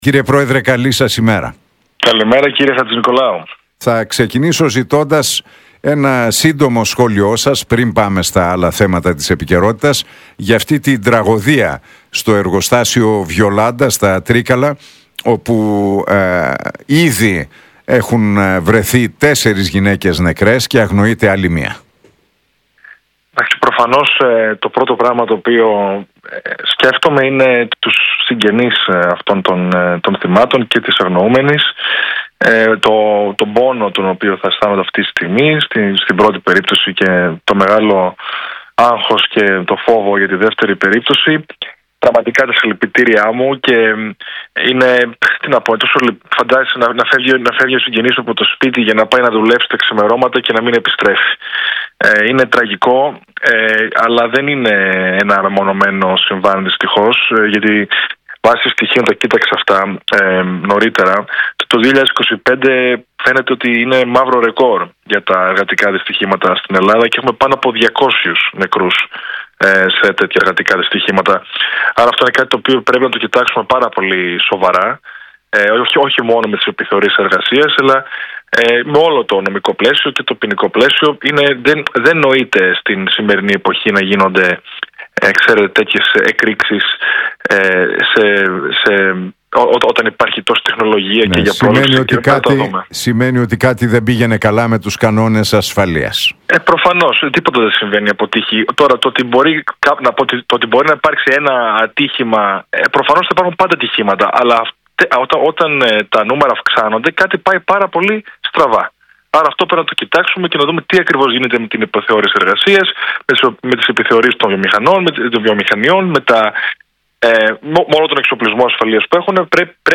Για τις διεθνείς εξελίξεις, τα ελληνοτουρκικά, τα νέα κόμματα που βρίσκονται στα σκαριά και για το πώς θα κινηθεί το Κίνημα Δημοκρατίας στις εκλογές μίλησε ο Στέφανος Κασσελάκης στον Realfm 97,8 και τον Νίκο Χατζηνικολάου.